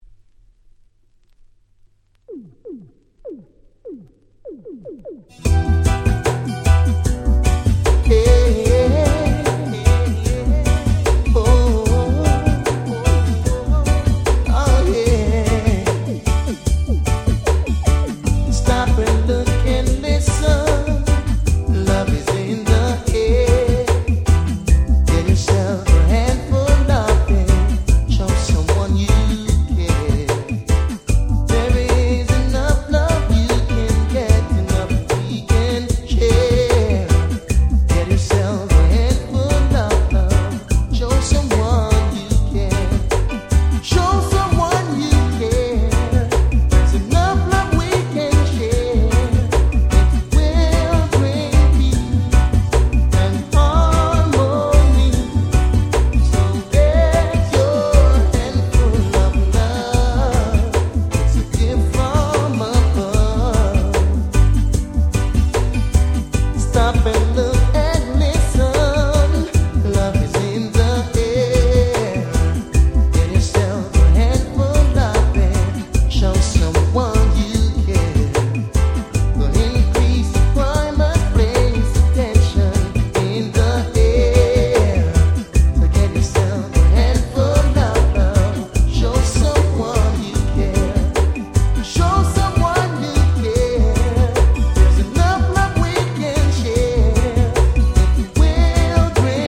96' Very Nice Reggae R&B !!